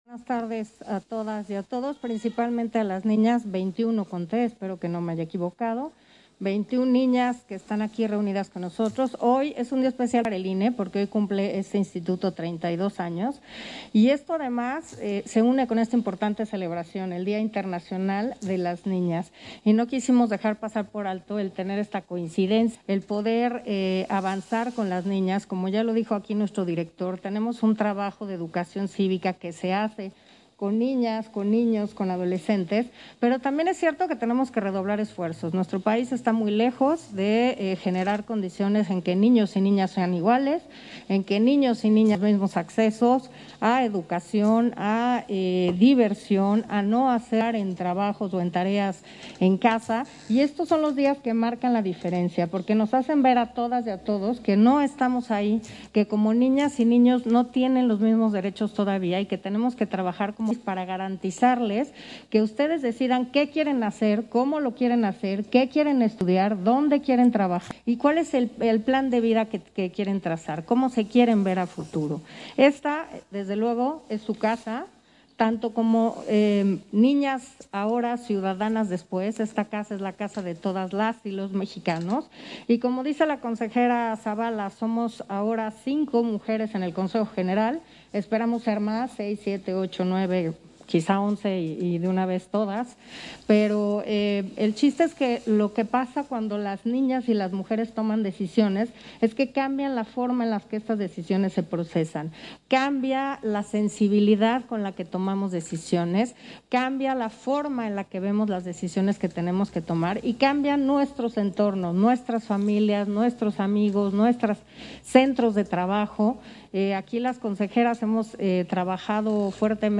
Intervención de Carla Humphrey, en la conmemoración del Día Internacional de la Niña